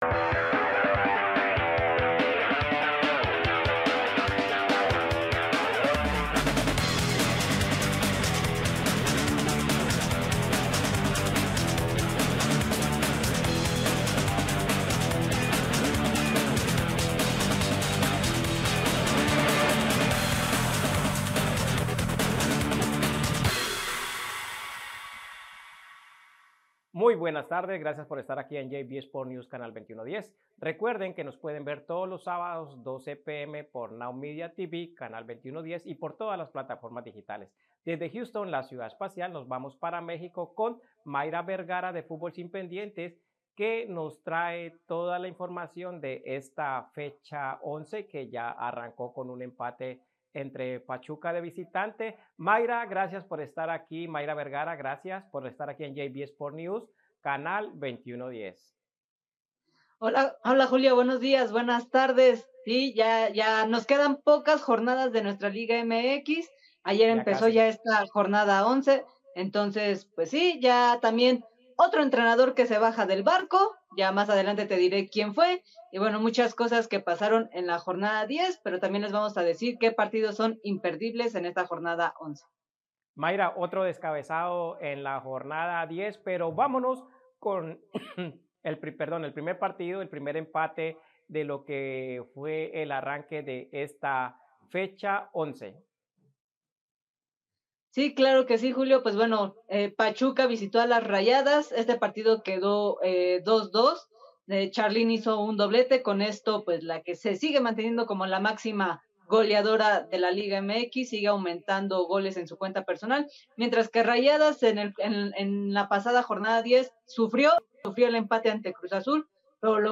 Todo sobre la jornada 2025: MVP destacado, resultados, nuevos equipos y entrevistas.